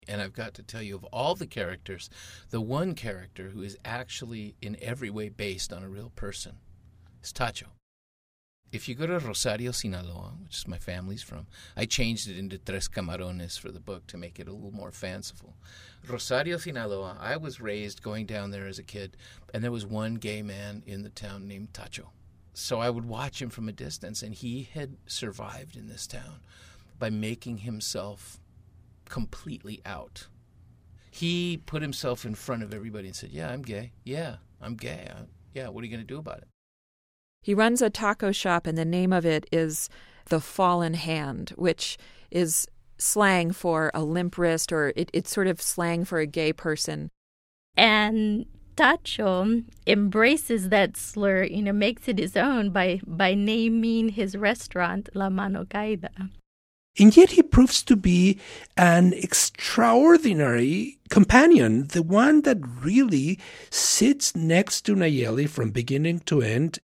Here’s an excerpt from our soon-to-be released audio guide about this wildly rich and fascinating novel.